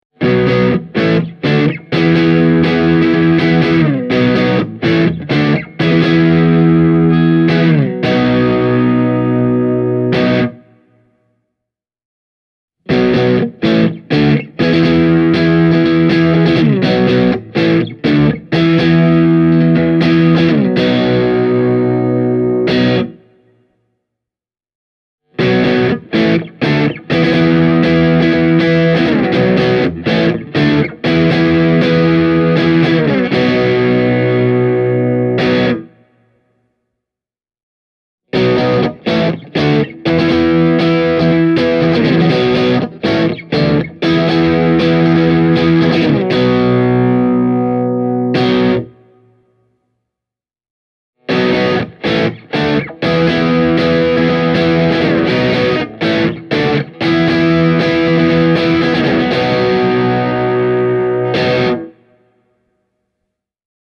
Testasin Carvin Legacy 3 -nupin vaahterakaulaisella Fender Stratocasterilla ja Hamer USA Studio Custom -kitaralla Zilla Cabsin 2 x 12” -kaapin kautta, ja tulokset puhuvat hyvin selkeää kieltä – tässä on kyseessä pro-luokan vahvistin.
Fender Stratocaster – kanava 2